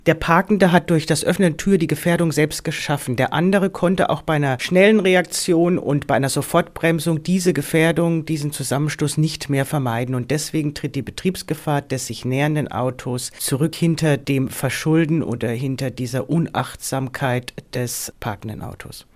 O-Ton: Wer haftet beim Öffnen der Autotür?